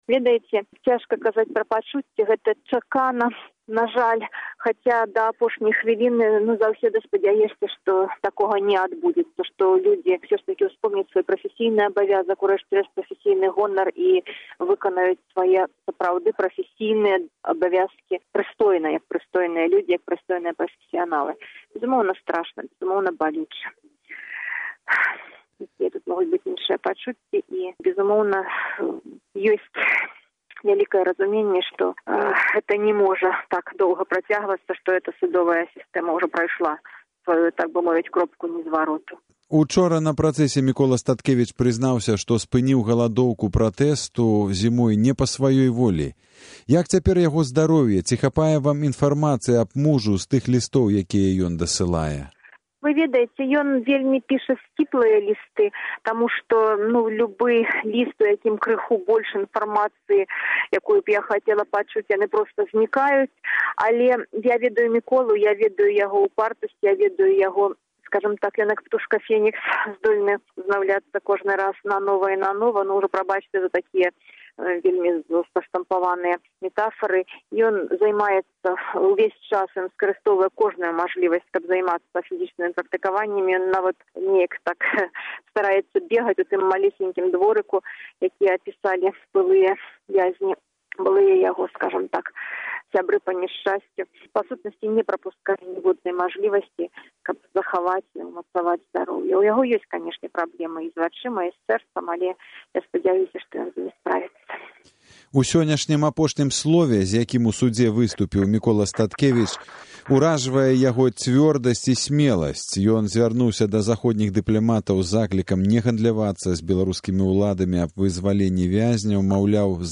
Размова